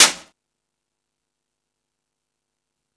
Index of /kb6/E-MU_Pro-Cussion/jazz drums
Jazz Drums(39).wav